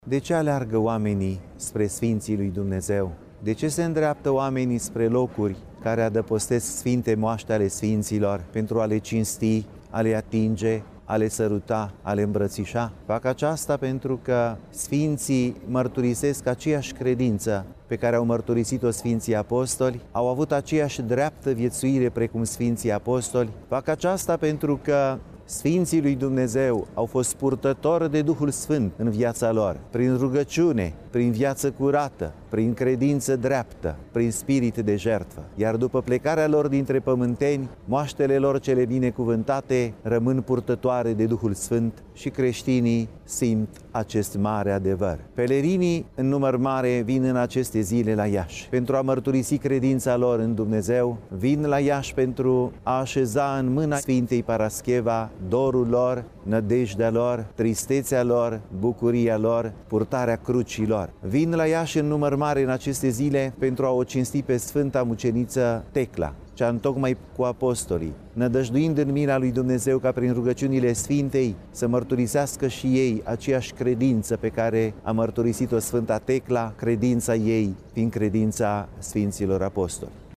Înaltpreasfinţitul Teofan, Mitropolitul Moldovei şi Bucovinei, a transmis un mesaj pelerinilor sosiţi în număr mare la Iaşi din toată ţara, dar şi din afara graniţelor ei: